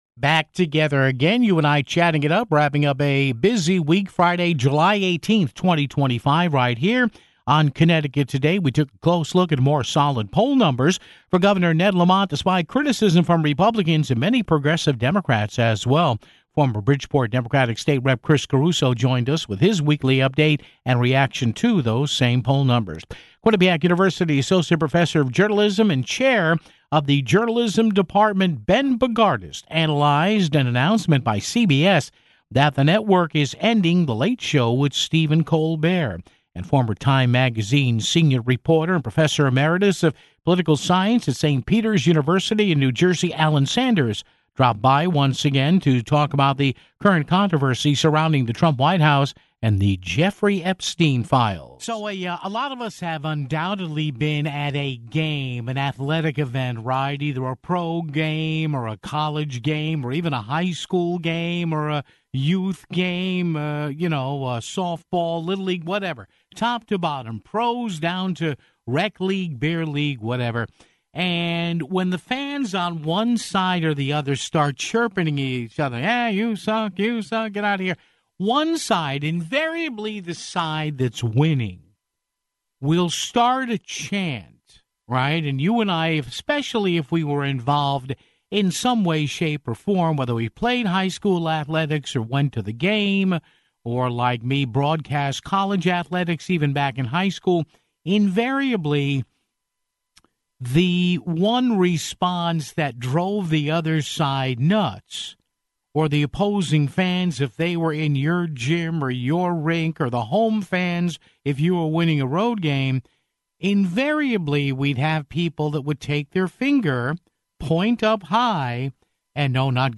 Former Bridgeport Democratic State Rep. Chris Caruso joined us with his weekly update and reaction to those poll numbers (16:51).